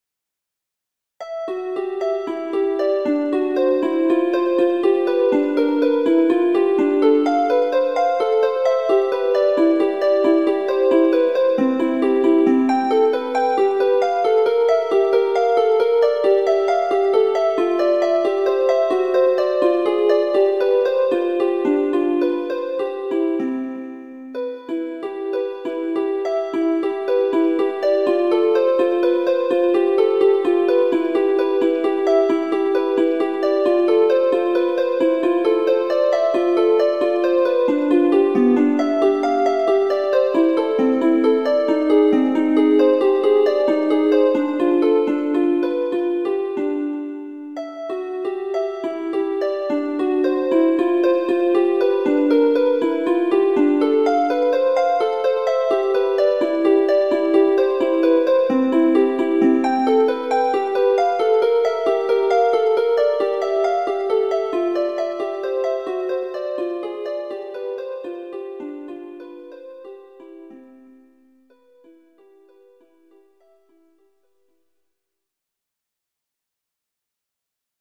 パート数が少ない分、誤魔化しできなくて耳コピ大変だった。